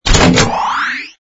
ui_equip_mount01.wav